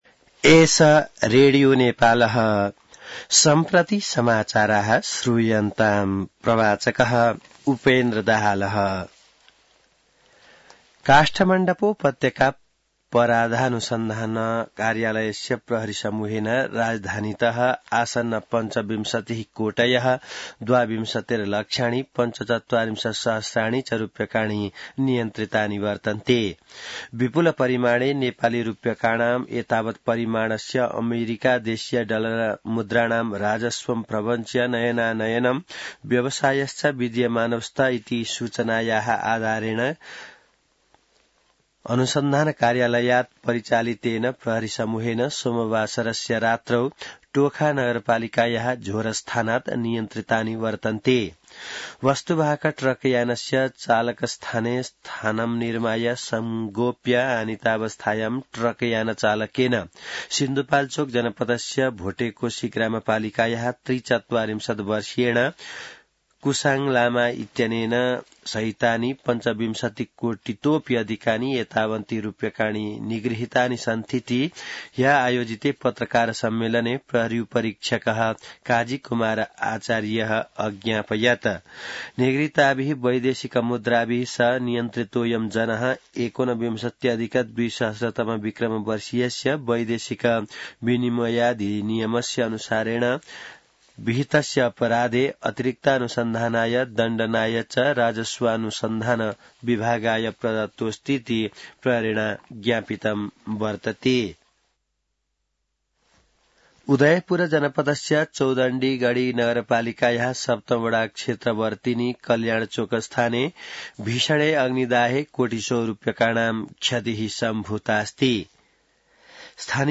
संस्कृत समाचार : ६ चैत , २०८१